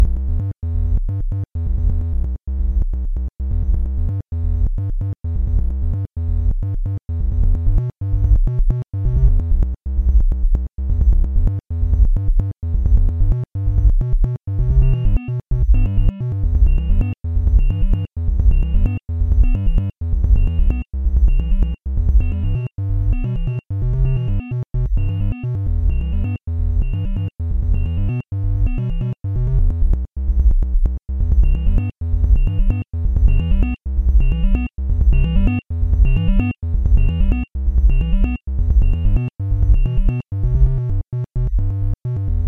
Tonights journey into layering, add playing with some sorta melody